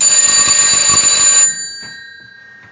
Tischtelefon Fg tist 264 b
Klingelton
0038_Klingelton.mp3